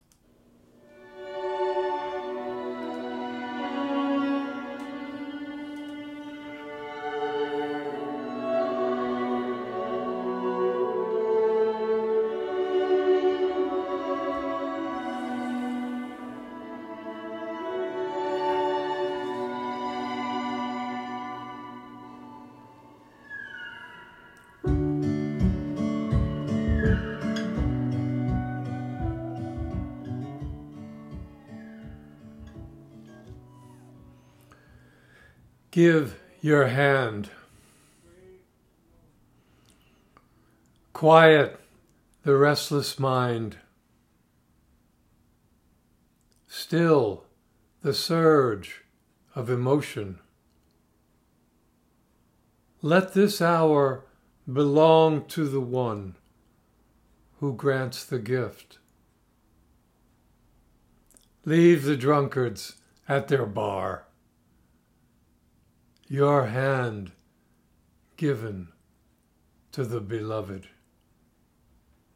Reading of “Give Your Hand” with music by Sturgill Simpson